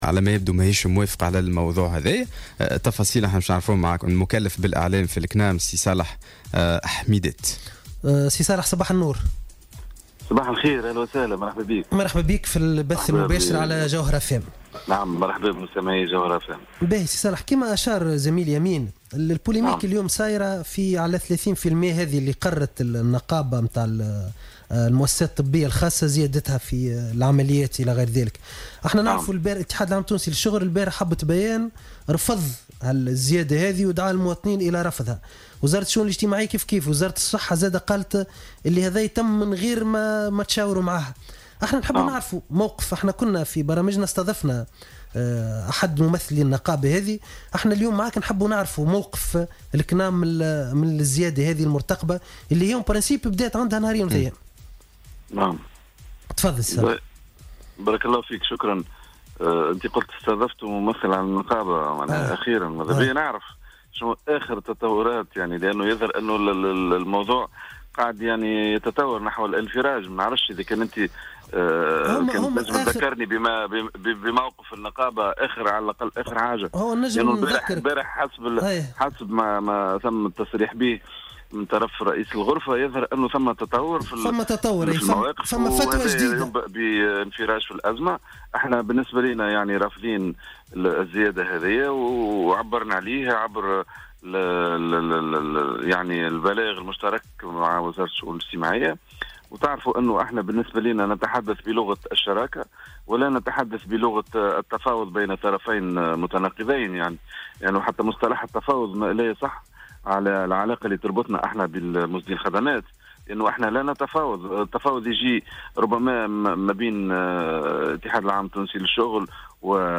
وأوضح في مداخلة مع "صباح الورد" على "الجوهرة أف أم" ان هناك مبلغا جزافيا يتكفل بتسديده "الكنام" والذي تم رفعه الى 8 آلاف دينار في القطاع العام عند القيام بعملية جراحية تهم اساسا القلب والشرايين ويشمل أيضا القطاع الخاص.